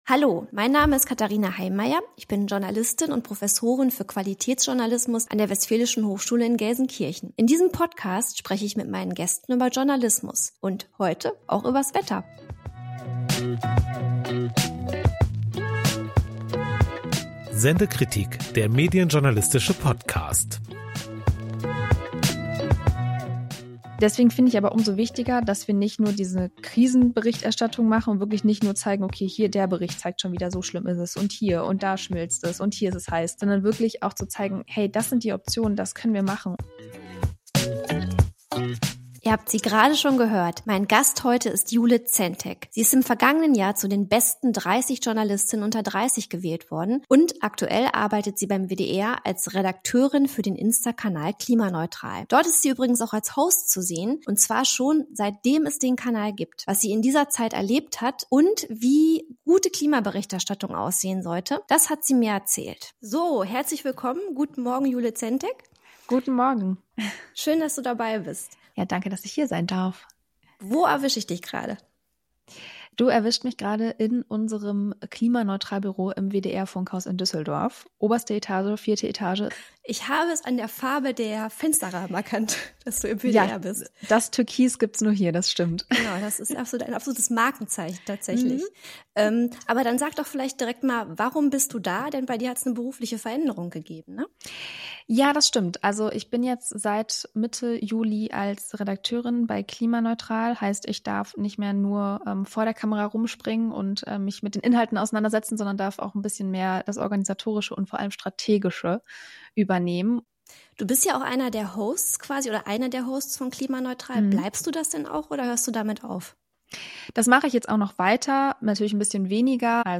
Ein Gespräch über Shitstorms, türkise Fensterrahmen und die Frage, warum Klimaberichterstattung mehr als Krisenberichterstattung sein sollte.